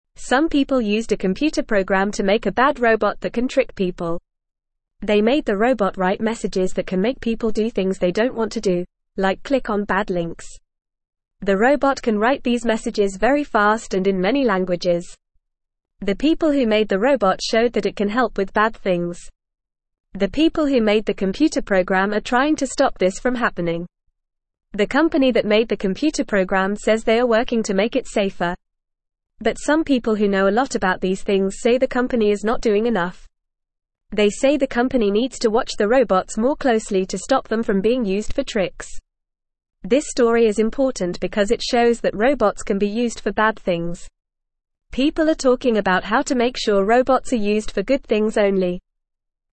Fast
English-Newsroom-Beginner-FAST-Reading-Bad-Robot-Tricks-People-Company-Working-to-Stop-It.mp3